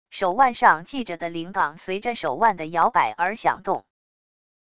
手腕上系着的铃铛随着手腕的摇摆而响动wav音频